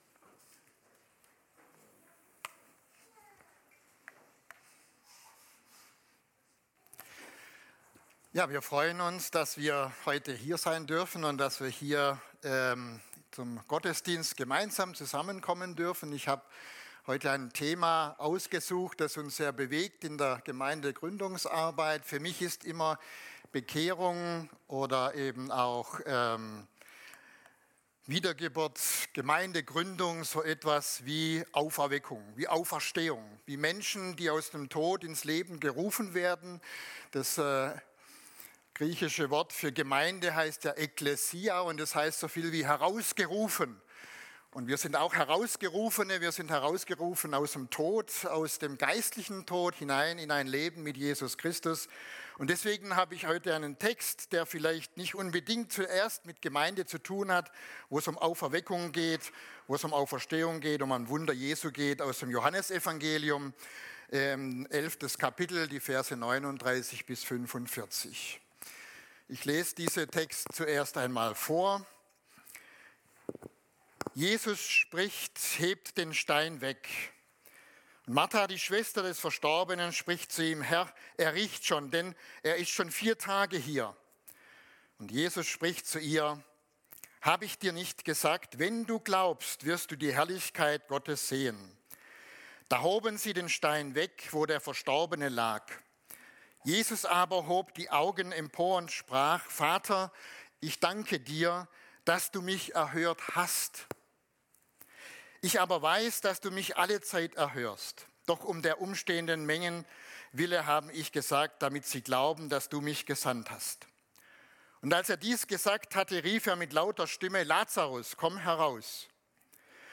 Gottesdienst am 09.02.2024